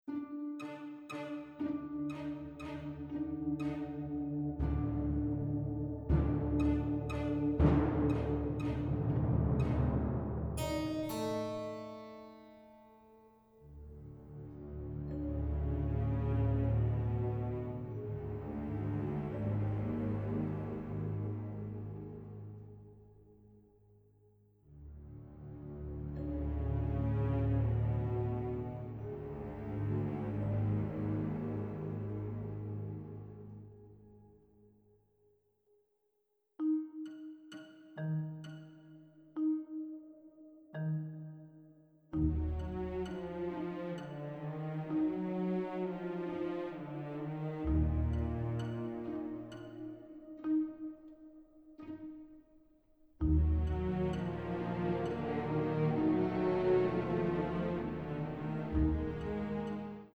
wit, slapstick, mystery and suspense with a classy touch